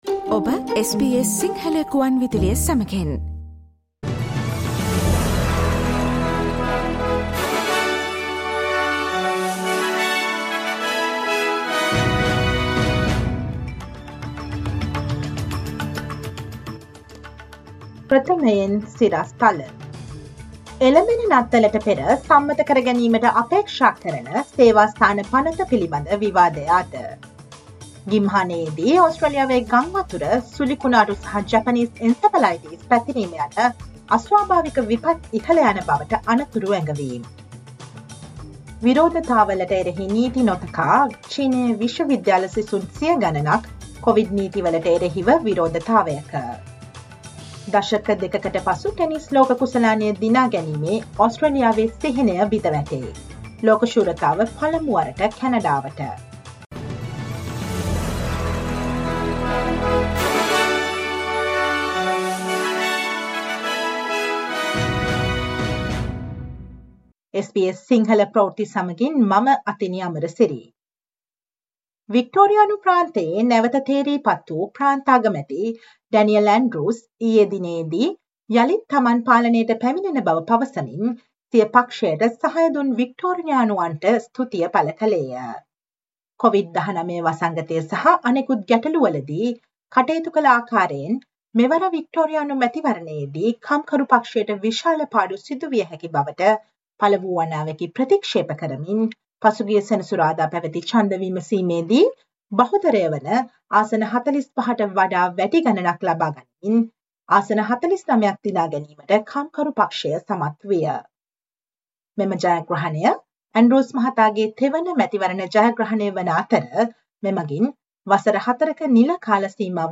Listen to the SBS Sinhala Radio news bulletin on Thursday, 24 November 2022